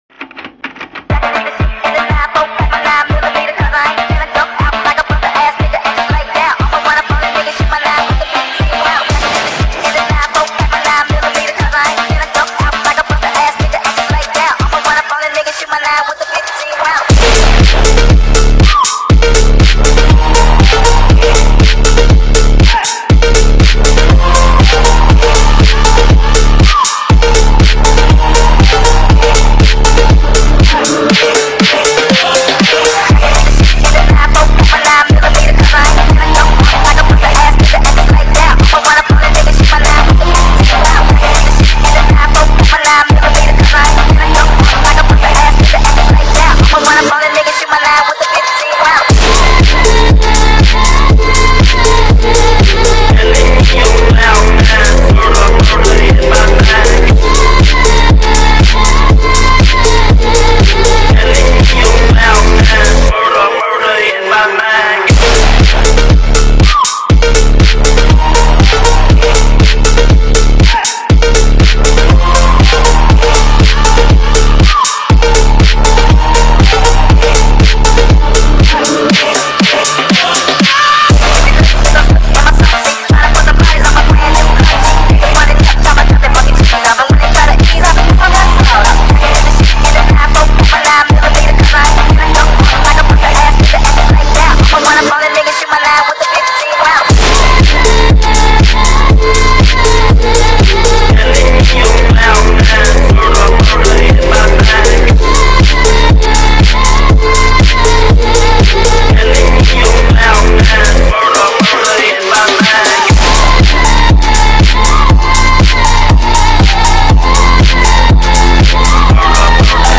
"فانک" (Phonk)